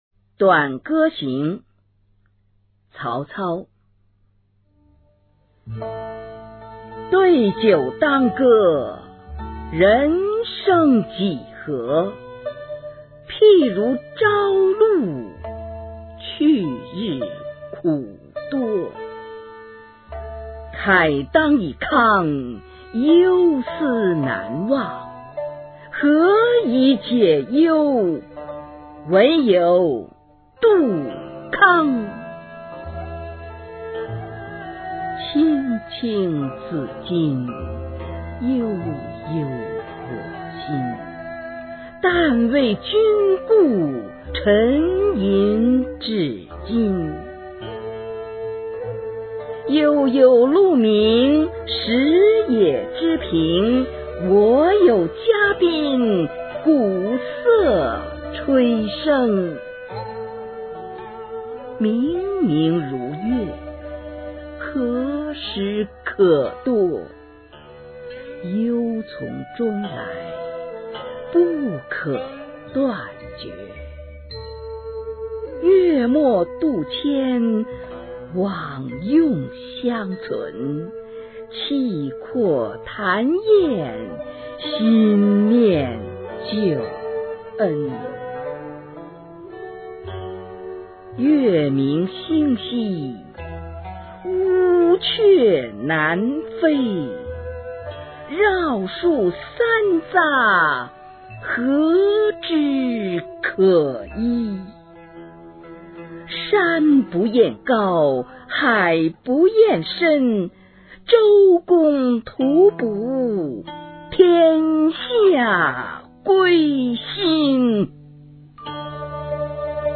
曹操《短歌行》原文、翻译、赏析、朗读